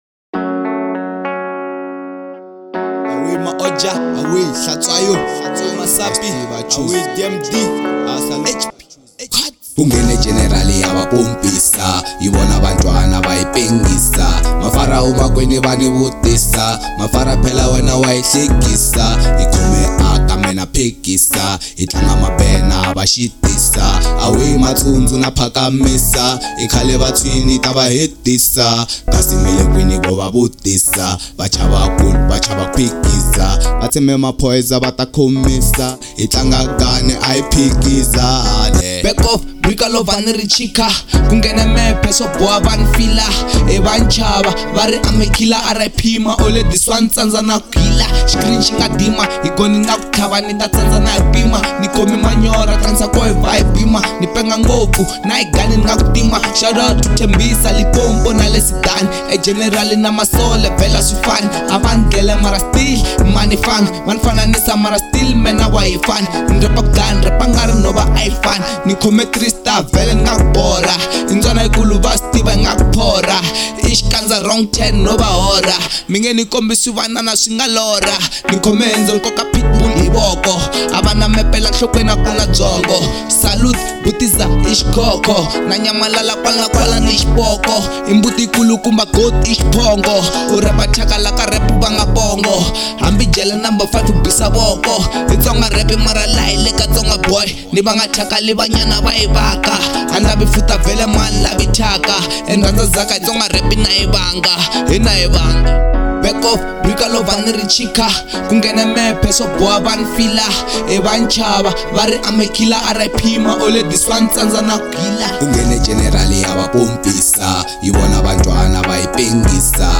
02:37 Genre : Hip Hop Size